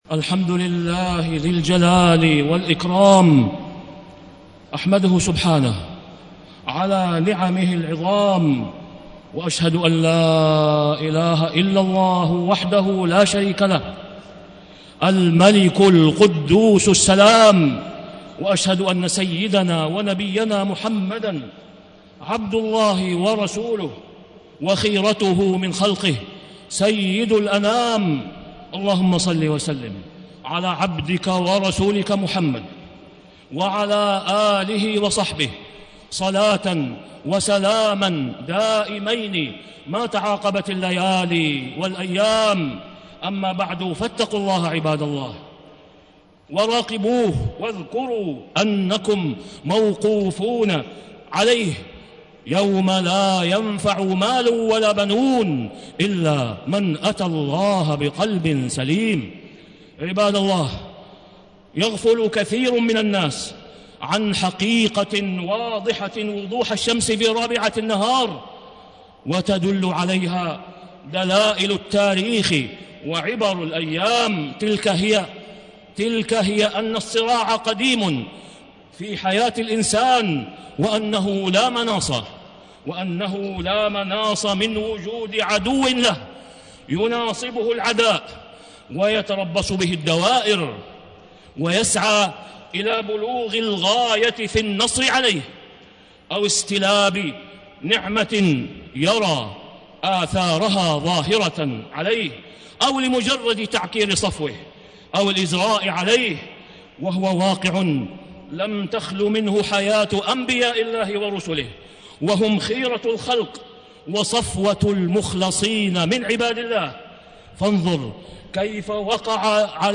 تاريخ النشر ٢٣ ذو الحجة ١٤٣٥ هـ المكان: المسجد الحرام الشيخ: فضيلة الشيخ د. أسامة بن عبدالله خياط فضيلة الشيخ د. أسامة بن عبدالله خياط الصراع بين الحق والباطل